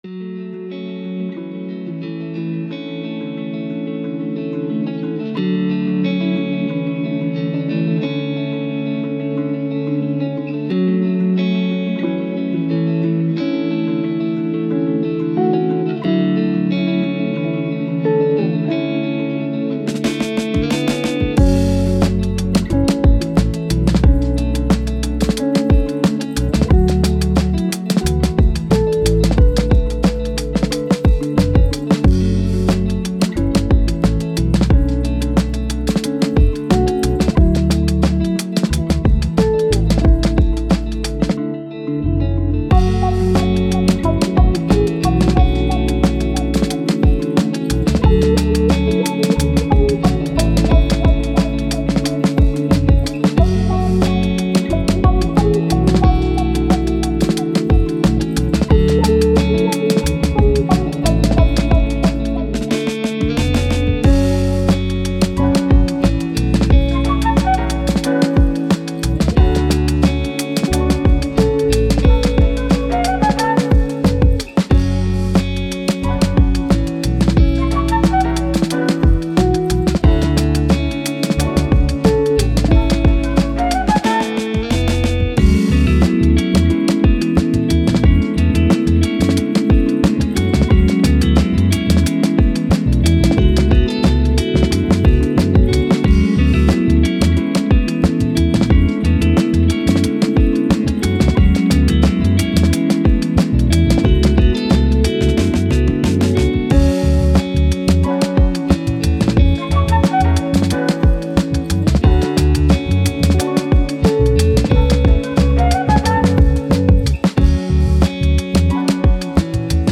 Melancholic, Thoughtful, Guitars, Downtempo